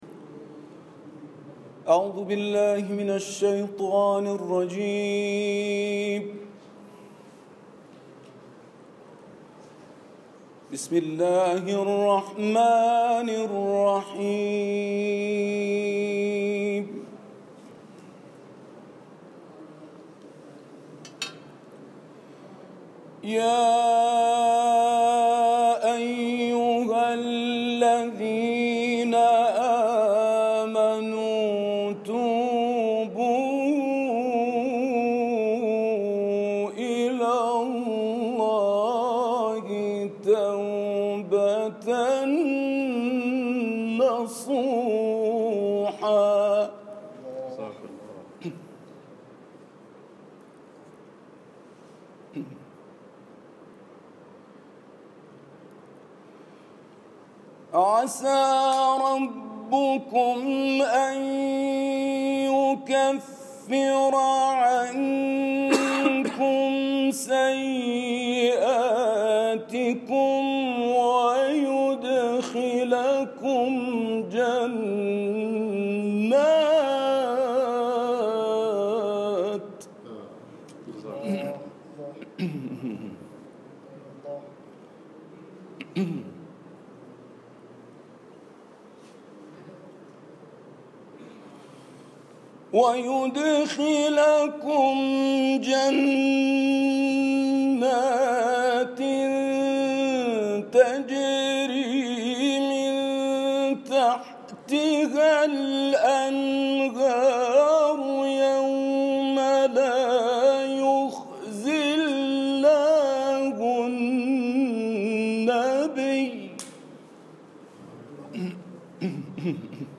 تلاوت کوتاه
در برنامه زنده تلویزیونی کشور هند
در پخش زنده شبکه یا زهرا(س) شهر بنگلور